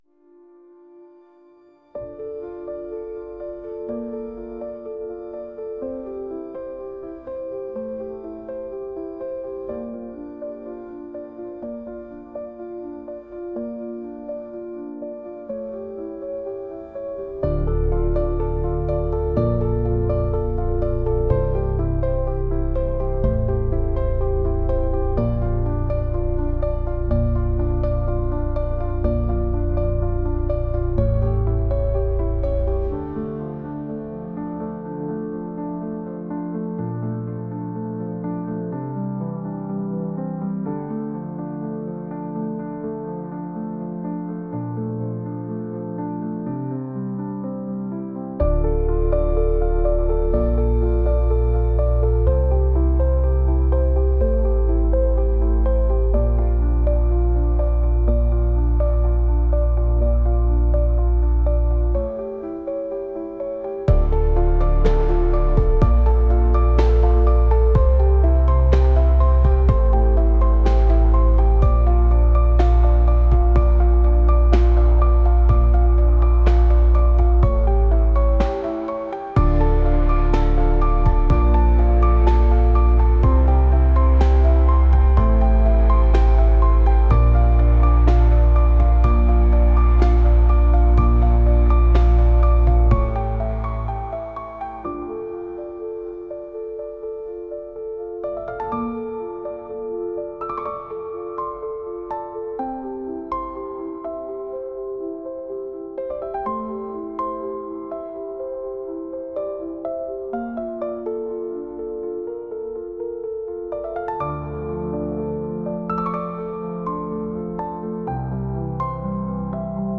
분위기 긴장감
BPM 60